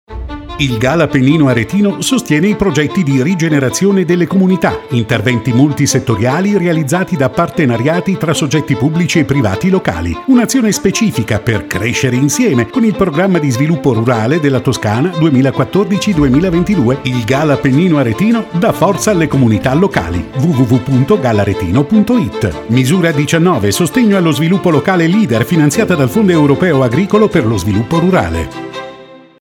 Radio Valtiberina  spot n. 3
Spot-n.-3-Radio-Valtiberina-1.mp3